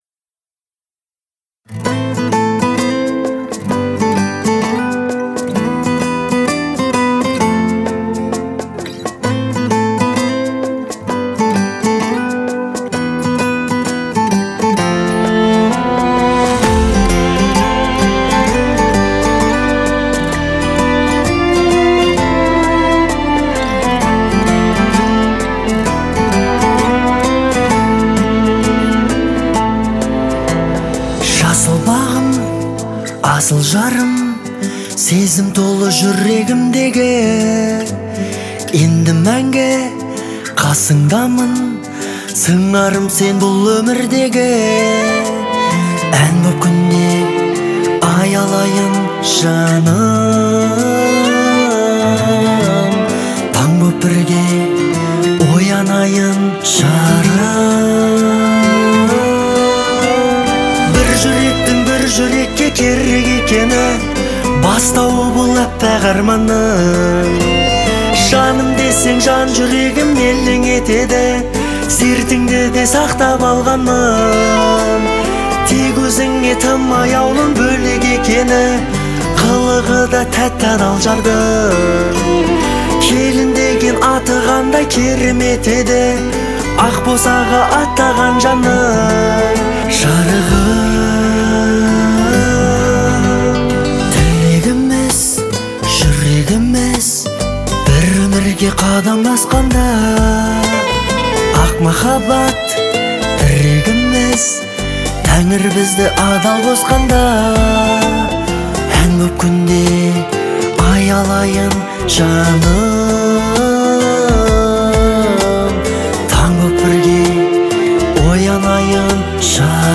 это трогательная песня в жанре поп